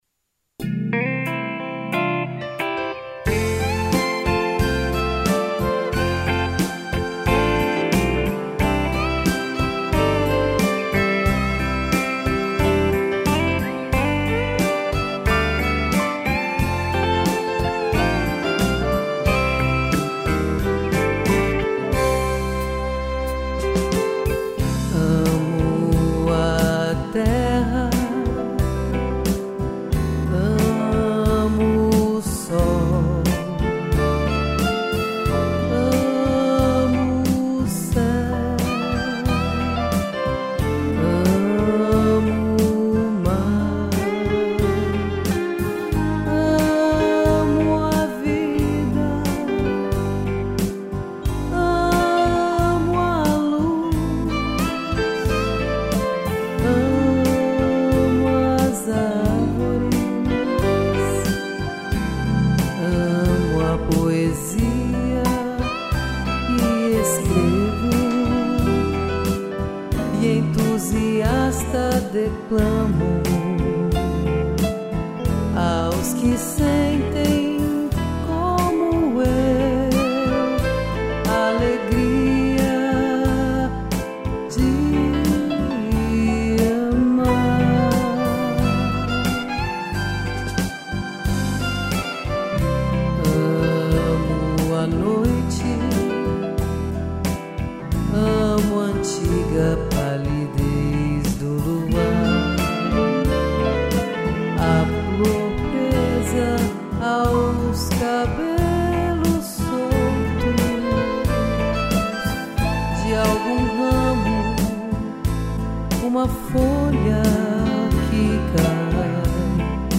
piano e violino